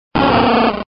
Archivo:Grito de Magby.ogg
actual17:49 26 oct 20140,8s (14 kB)PoryBot (discusión | contribs.)Actualizando grito en la sexta generación (XY)